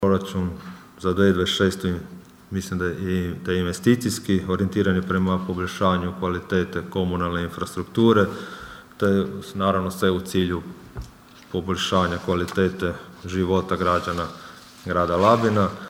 ton – Donald Blašković 1), zaključio je gradonačelnik Donald Blašković.